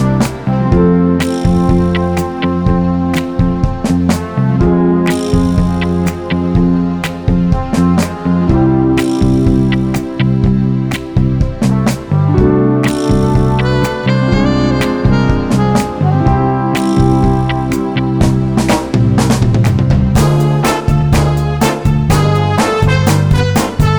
Version 1 Crooners 3:26 Buy £1.50